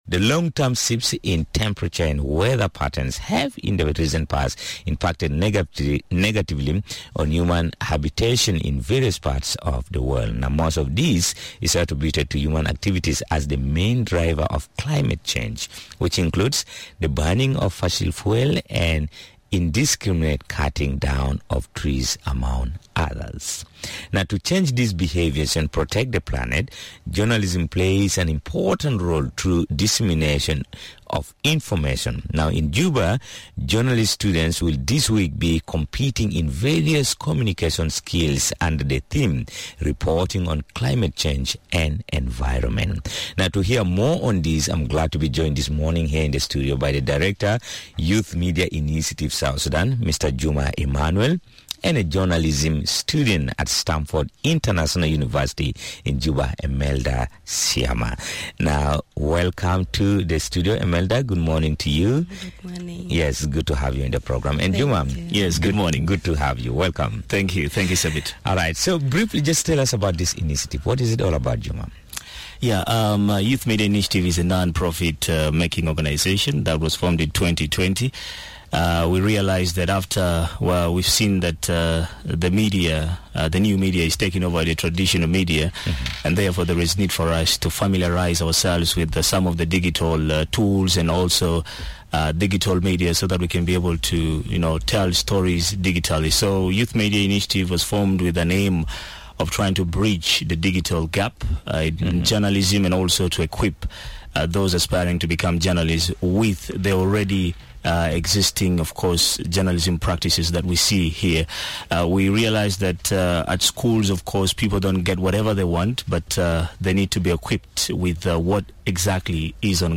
More about this competition in this interview with a Director